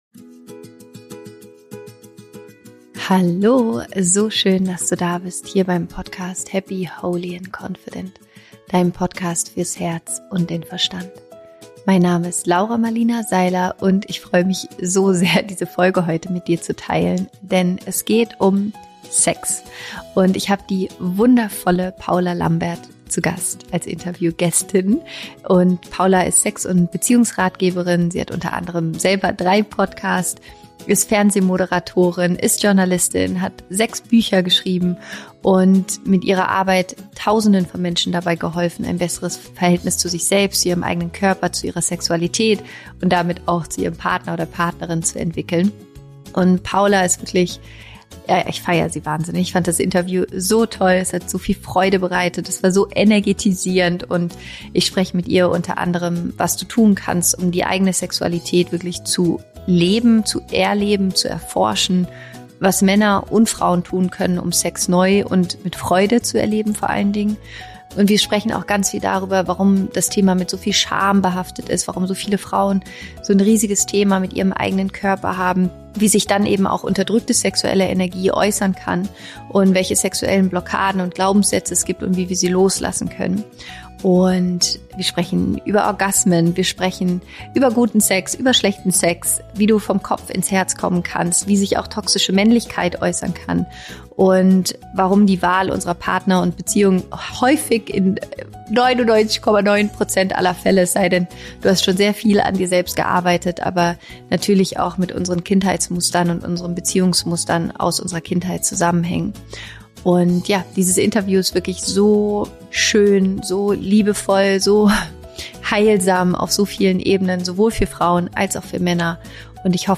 Mit der inspirierenden Sex- und Beziehungsratgeberin Paula Lambert spreche ich darüber, was es braucht, um richtig guten und erfüllten Sex zu haben.